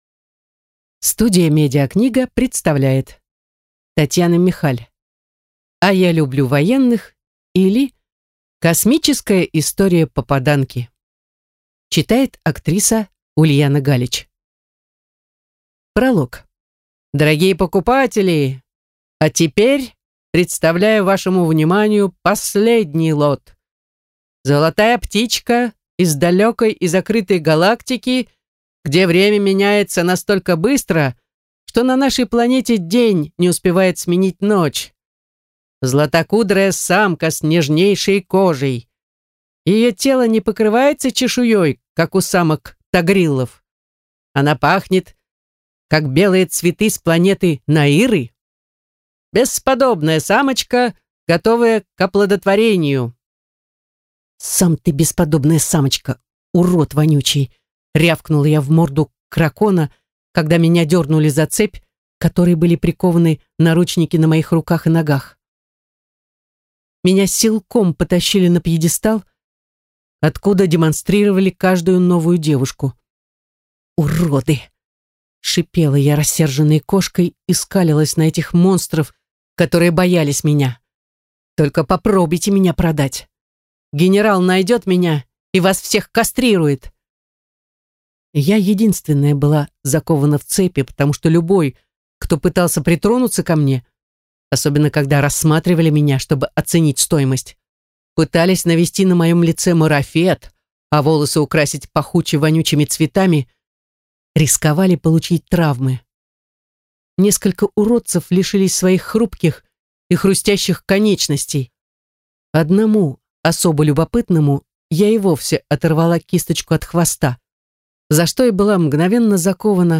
Аудиокнига А я люблю военных, или космическая история попаданки | Библиотека аудиокниг